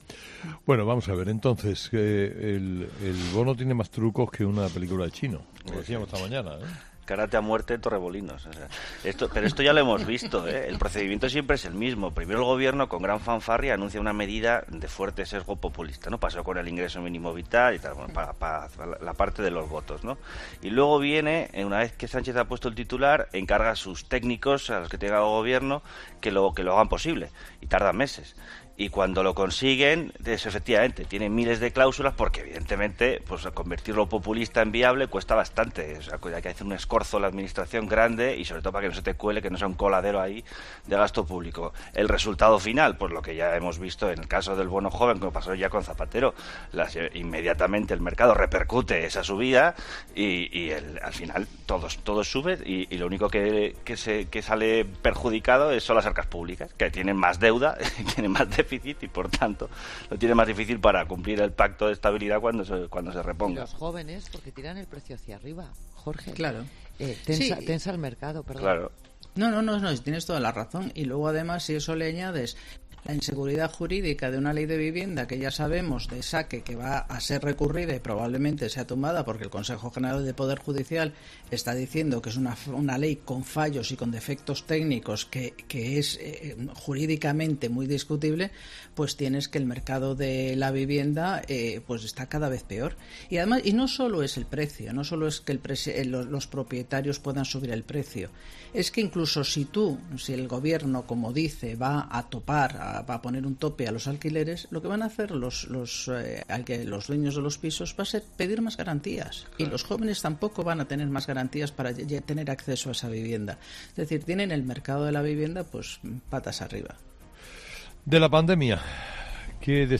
AUDIO: La tertulia de 'Herrera en COPE' analiza las ayudas del bono joven de alquiler